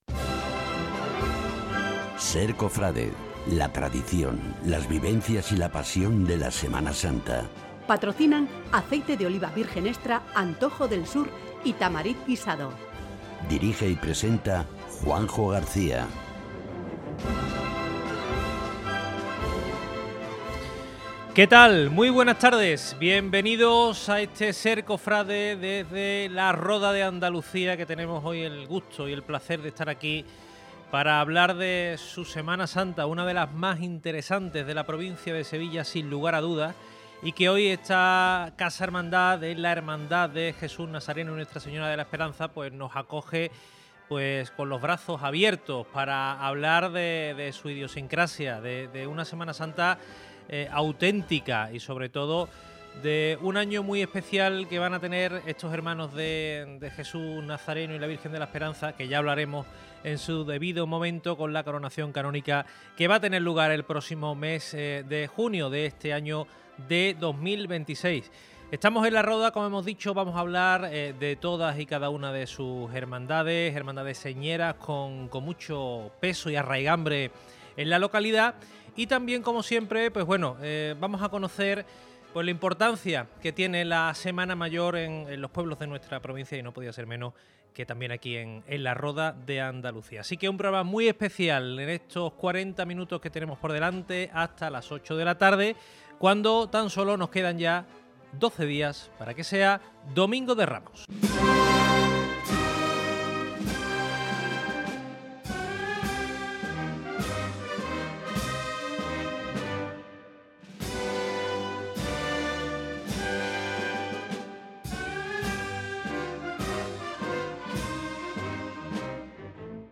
SER COFRADE 170326 LA RODA DE ANDALUCIA Programa especial SER Cofrade dedicado a la Semana Santa de La Roda de Andalucía, emitido el martes 17 de marzo de 2026 desde la casa hermandad de Ntro. Padre Jesús Nazareno de La Roda.